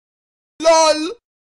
LOL Sound